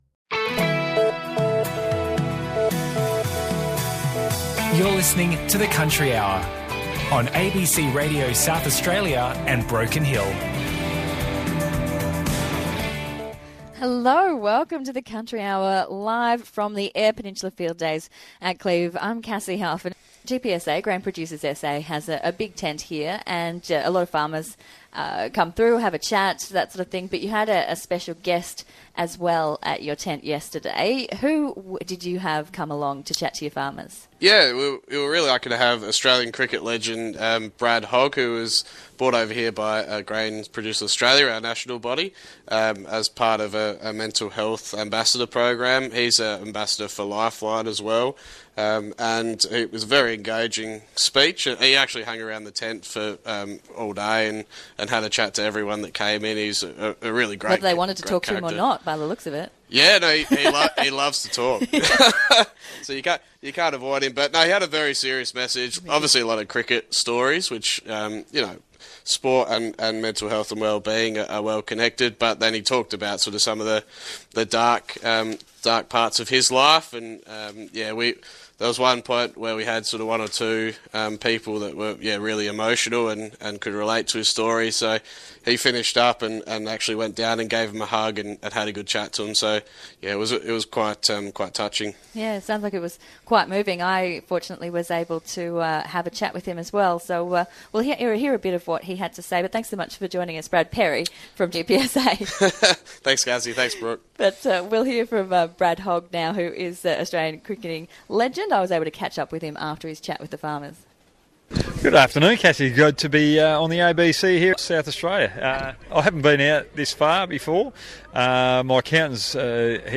SA COUNTRY HOUR | Interview with Brad Hogg, Farmer Mates Mental Health Ambassador